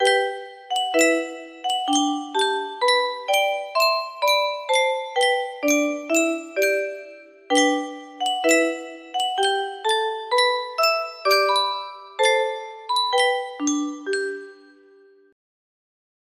Yunsheng Music Box - Men of Harlech 1487 music box melody
Full range 60